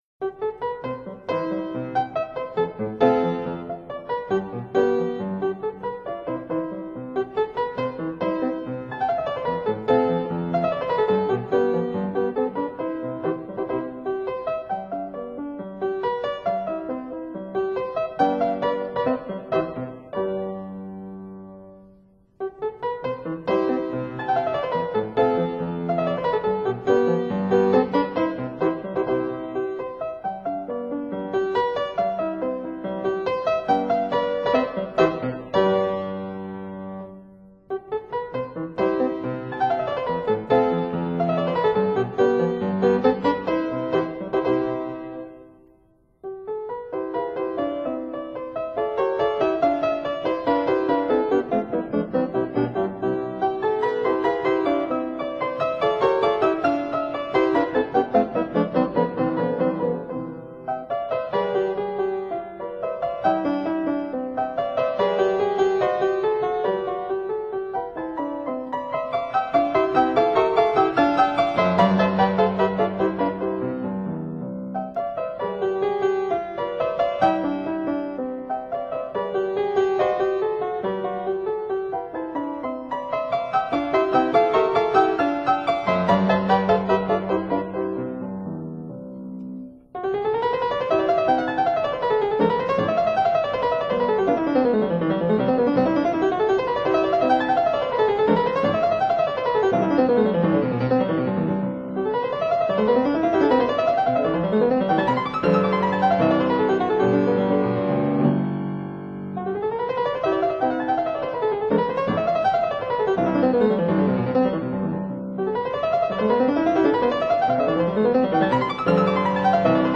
Fortepiano